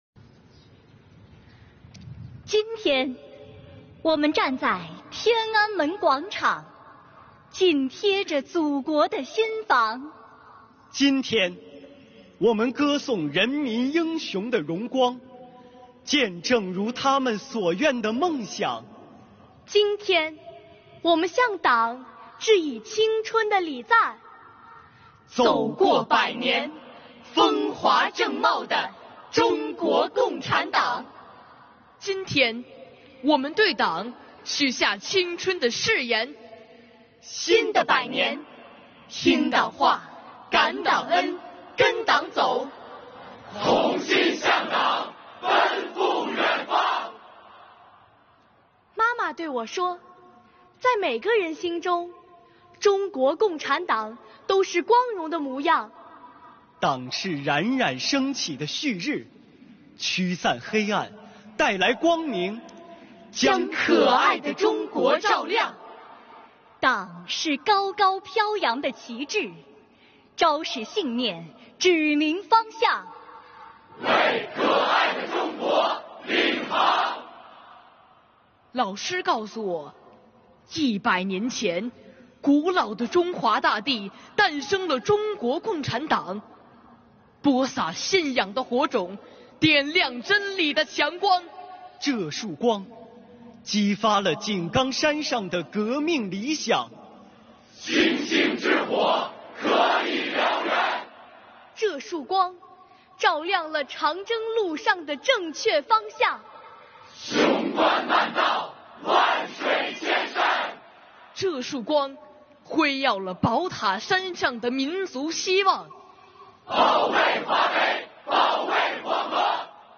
在北京天安门广场隆重举行的
庆祝中国共产党成立100周年大会
共青团员和少先队员代表们
集体致献词